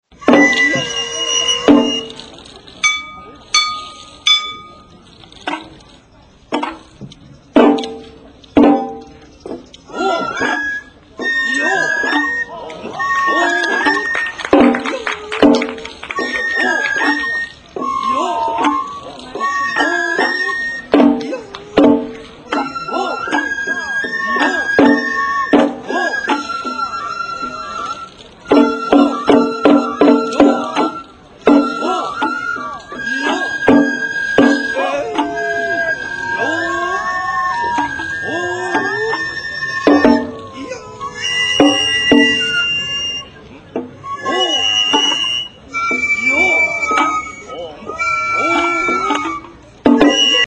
尾張地方の山車囃子は、能楽を基本に編曲された楽曲が多く、主に大太鼓、締太鼓、小鼓、そして笛（能管と篠笛）によって演奏されます。
人形囃子後半
からくり人形（倒立唐子）の演技の際に演奏される。